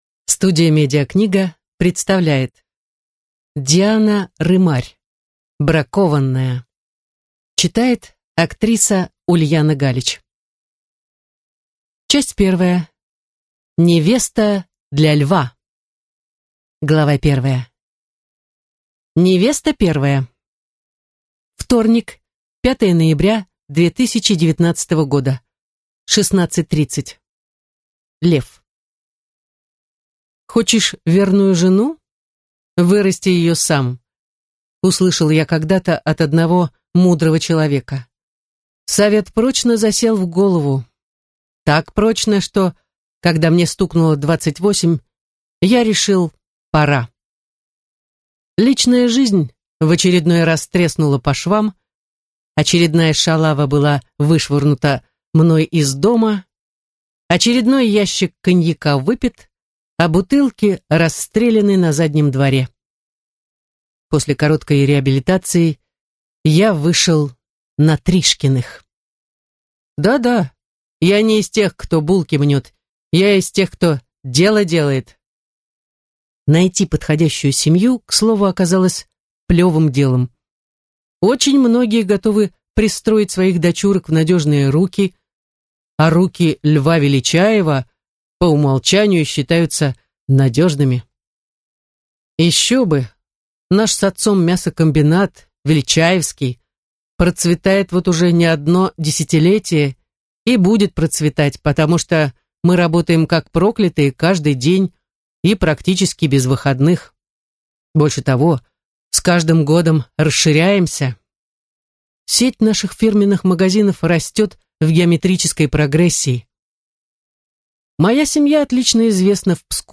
Аудиокнига Бракованная | Библиотека аудиокниг
Прослушать и бесплатно скачать фрагмент аудиокниги